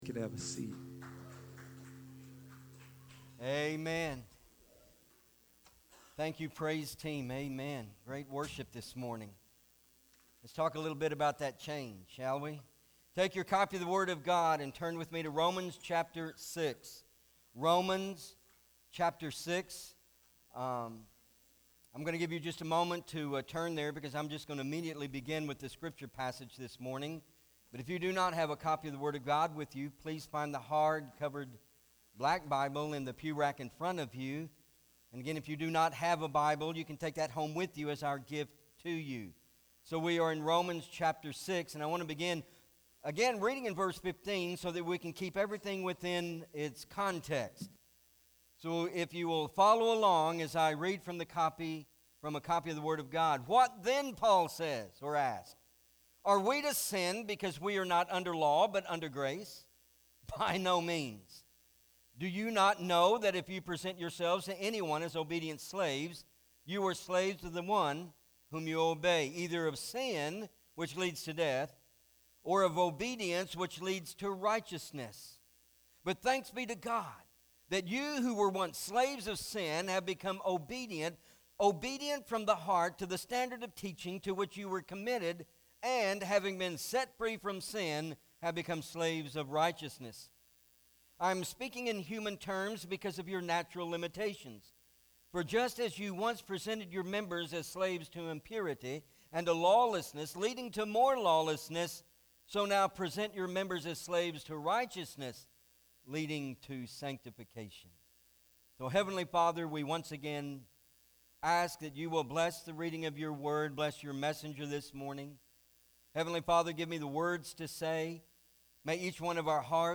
Romans Revealed- Slaves of Righteousness-Pt 2 MP3 SUBSCRIBE on iTunes(Podcast) Notes Sermons in this Series Romans 6: 15-19 Not Ashamed!